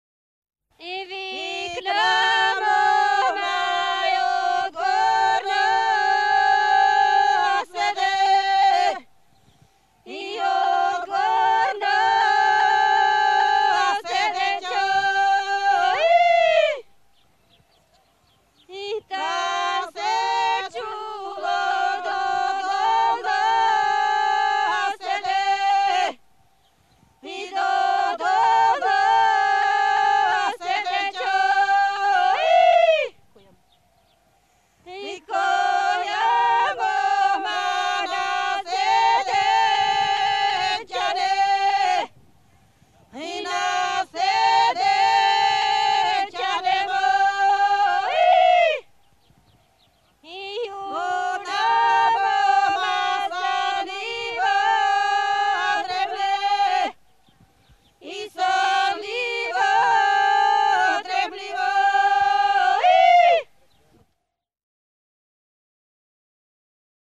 Bulgaria 1955